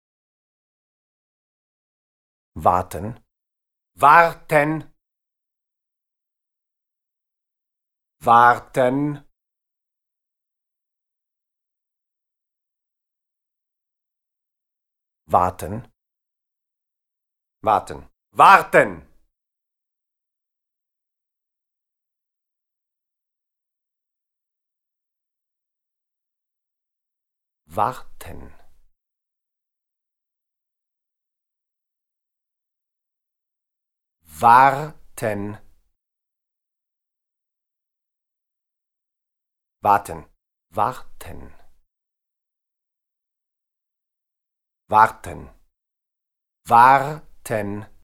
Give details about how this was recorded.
Multi-source Sound (loops) The resulting three short recordings were installed in the corridor of the Delphi Theatre and ran as loops for the duration of the performance.